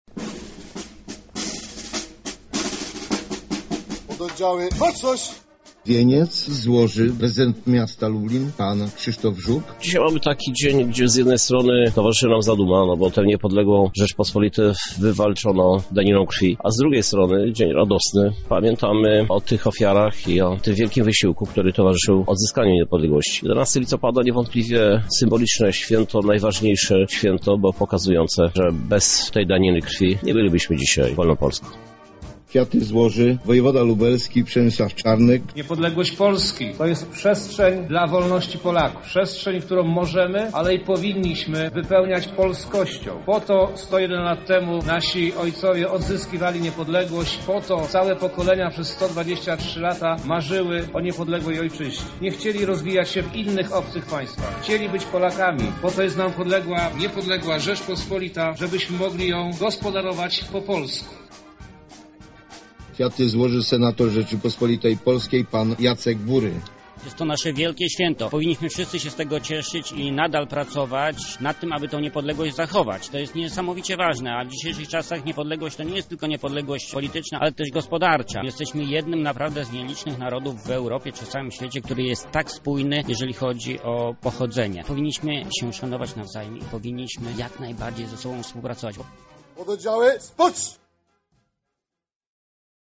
Przebieg uroczystości śledził nasz reporter.